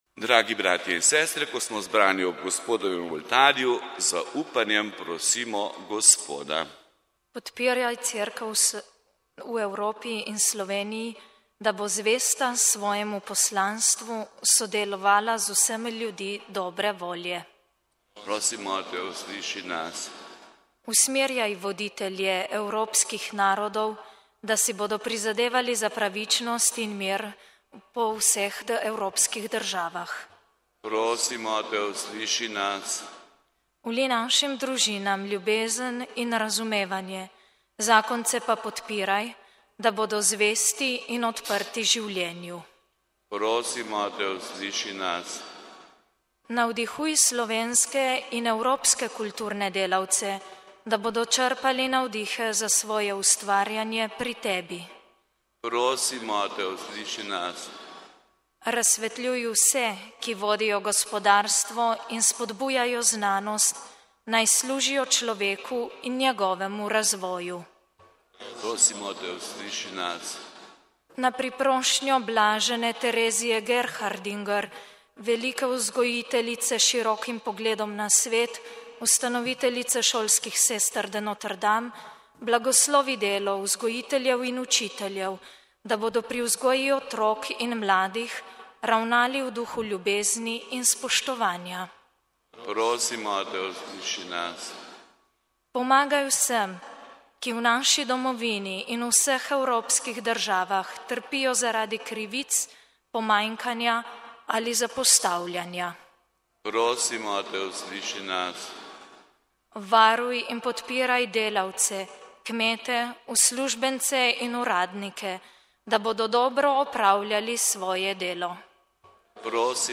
S temi besedami je ljubljanski pomožni škof Anton Jamnik začel pridigo med sveto mašo, ki jo je daroval v ljubljanski stolnici na dan Evrope, ko se spominjamo Schumanove deklaracije in formalnega začetka povezovanja med državami.
Pridiga